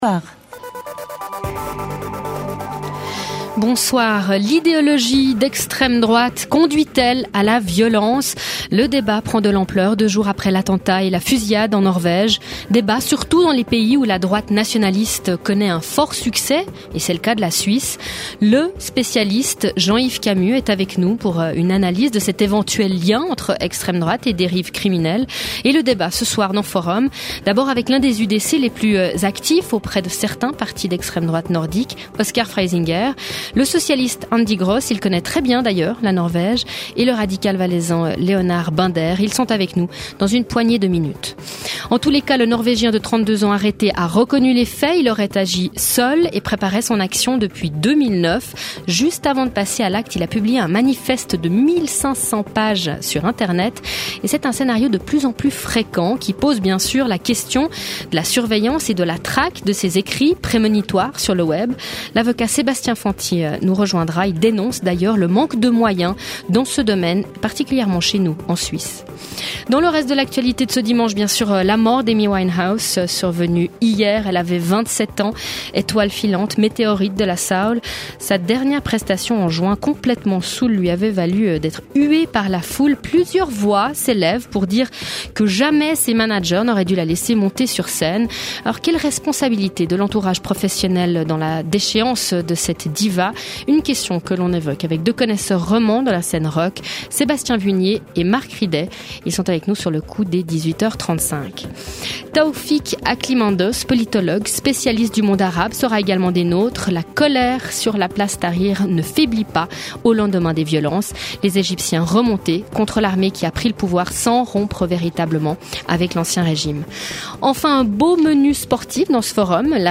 7 jours sur 7, Forum questionne en direct les acteurs de l’actualité, ouvre le débat sur les controverses qui animent la vie politique, culturelle et économique.